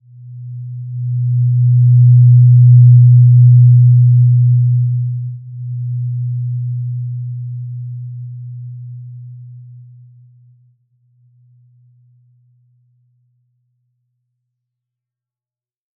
Simple-Glow-B2-mf.wav